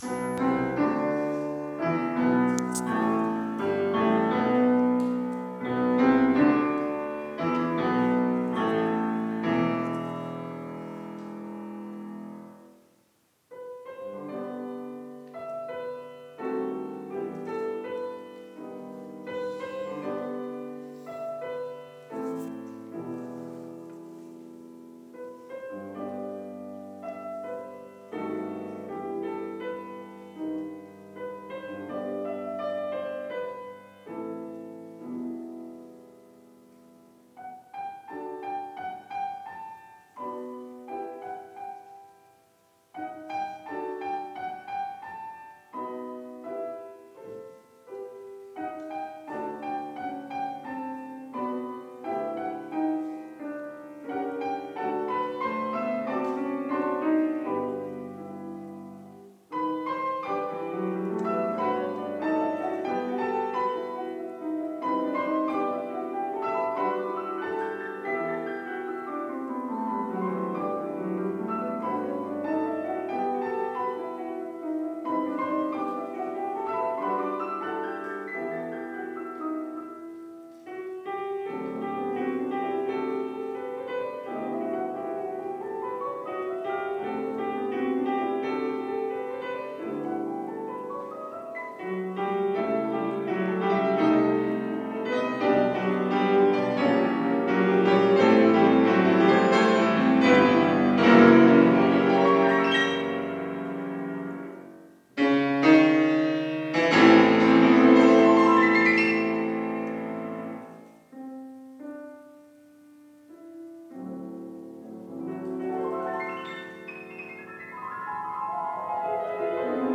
klavierduett 3